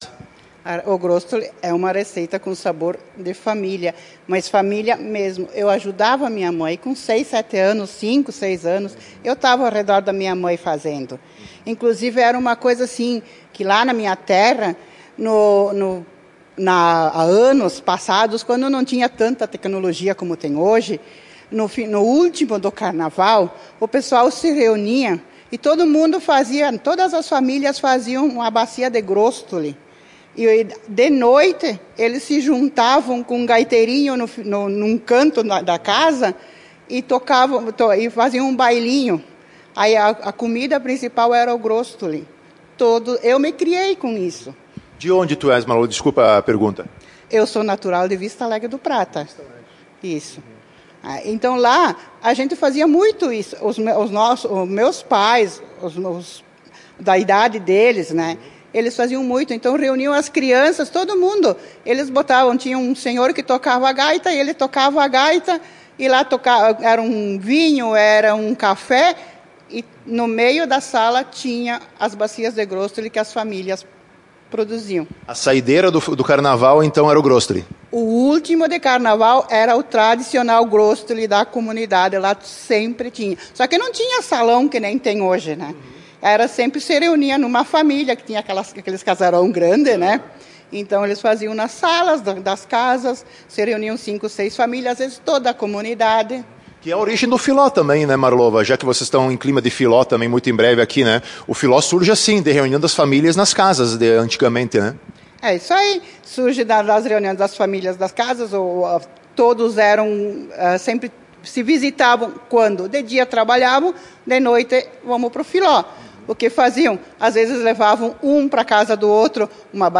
(entrevista em ouça a notícia)